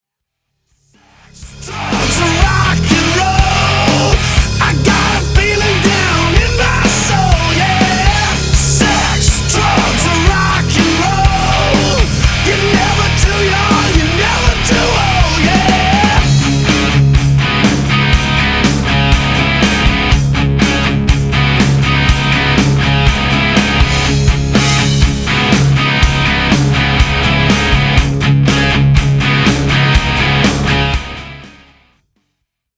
• Качество: 259, Stereo
громкие
брутальные
Драйвовые
электрогитара
Hard rock
heavy Metal
бунтарские